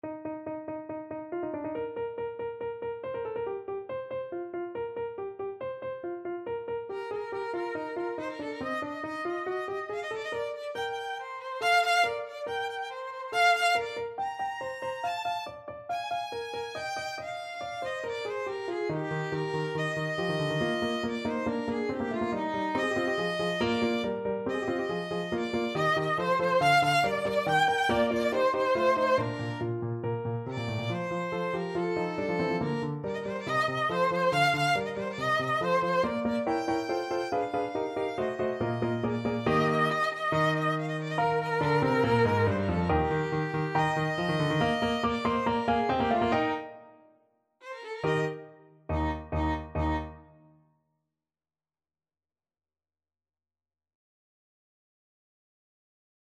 Eb major (Sounding Pitch) (View more Eb major Music for Violin )
= 140 Allegro (View more music marked Allegro)
Violin  (View more Intermediate Violin Music)
Classical (View more Classical Violin Music)